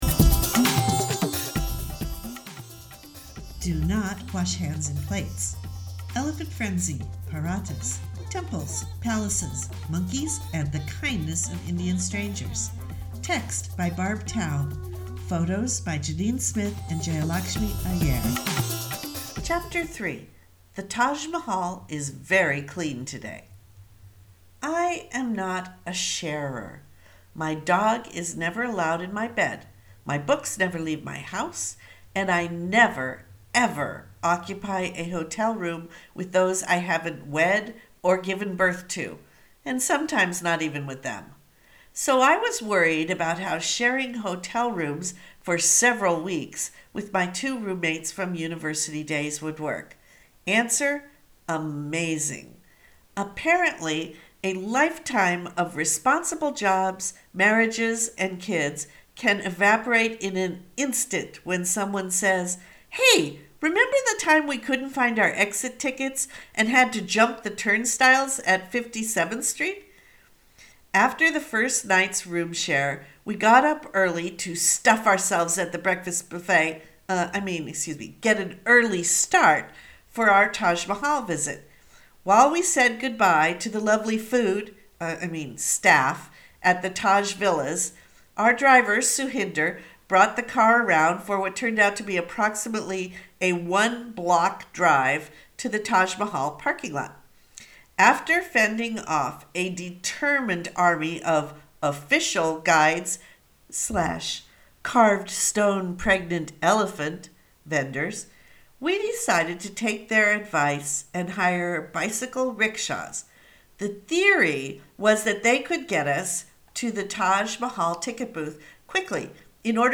Do you listen to audiobooks?